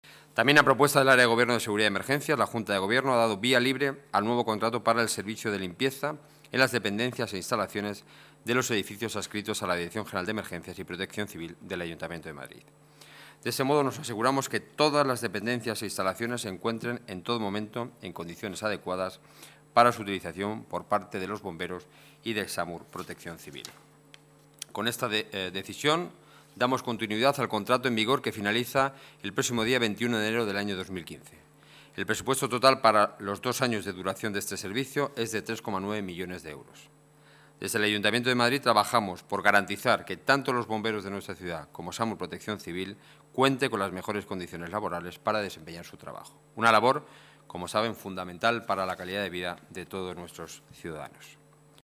Nueva ventana:Declaraciones de Enrique Núñez, portavoz del Gobierno muncipal